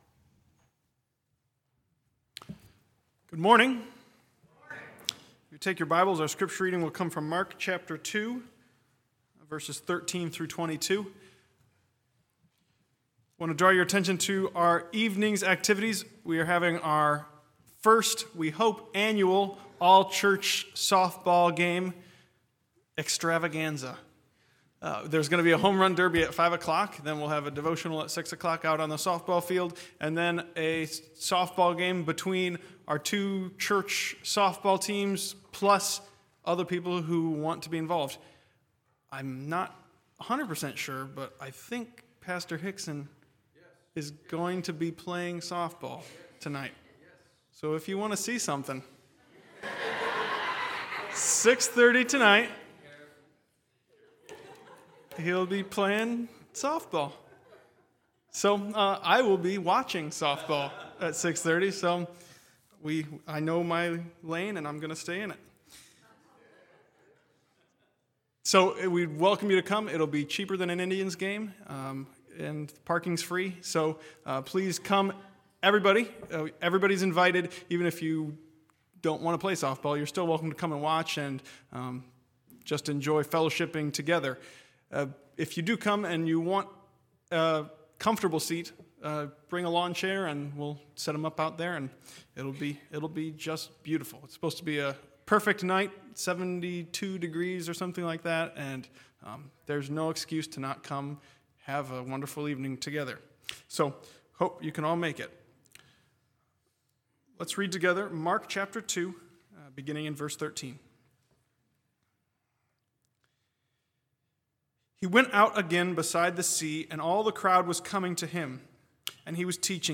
Bible Text: Mark 2:13-22 | Preacher